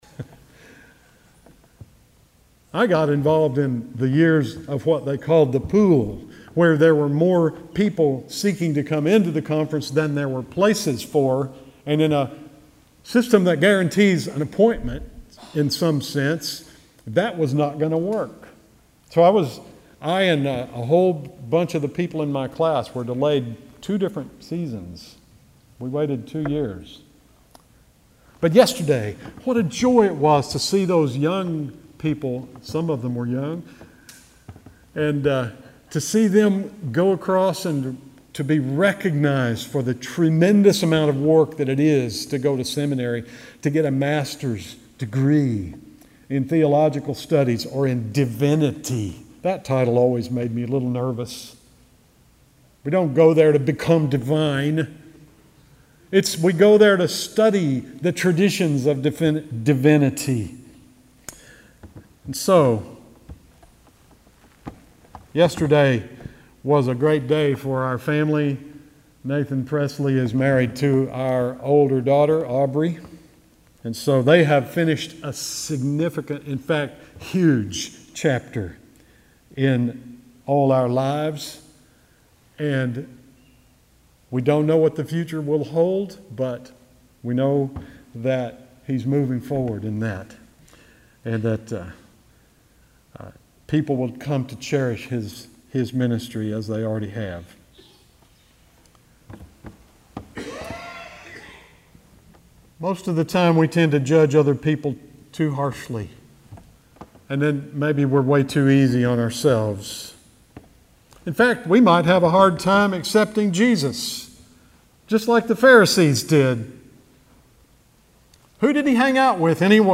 May 19, 2019 Sermon